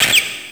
pokeemerald / sound / direct_sound_samples / cries / joltik.aif
-Replaced the Gen. 1 to 3 cries with BW2 rips.